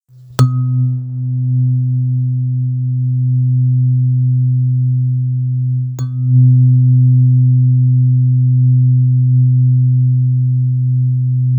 Čakrová terapeutická hliníková vážená ladička pro úspěch, nadání a bdělý život.
Materiál: hliník
Frekvence 126,22 Hz
Zvuková ukázka vážená ladička Solární čakra (wav, 997.5 kB)